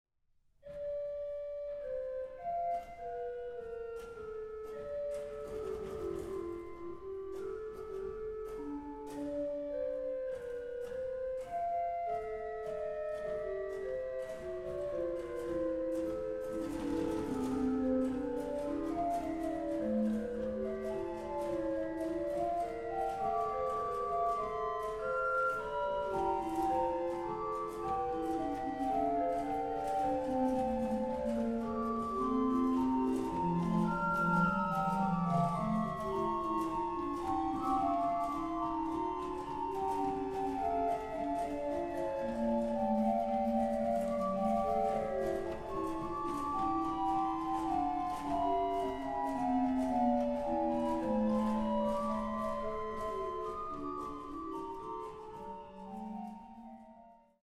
1755 erbaut für Prinzessin Anna Amalia von Preußen